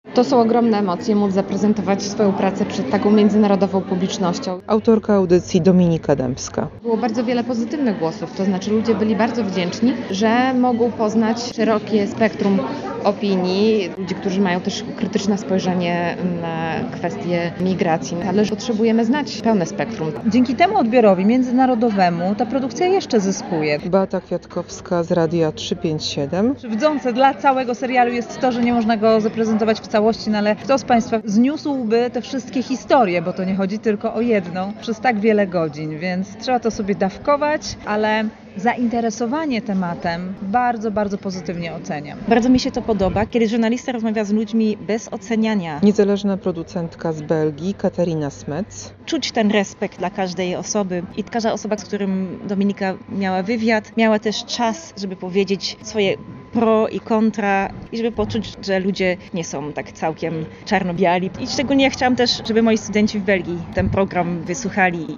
Publiczność wysłuchała fragmentów trzech odcinków o aktywistach, mieszkańcach i służbach mundurowych na 51. Festiwalu Audio Storytellingu w stolicy Litwy.